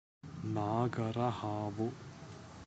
pronunciation transl.